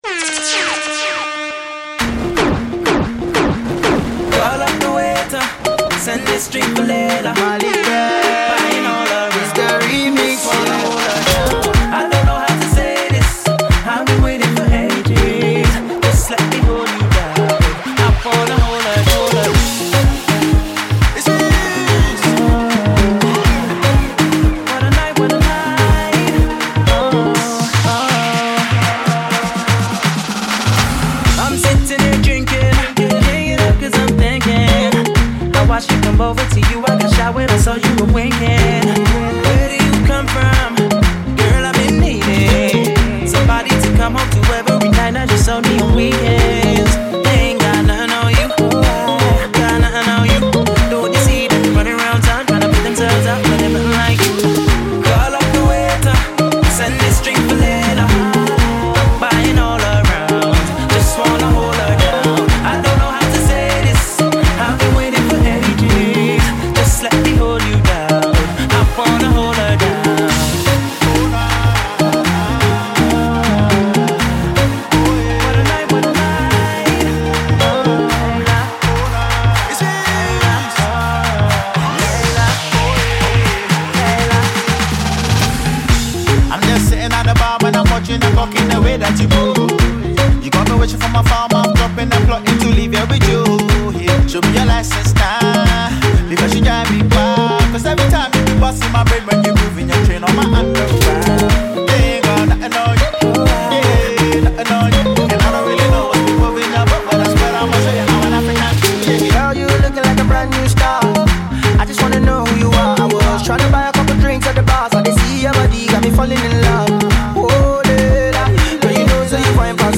UK RnB artiste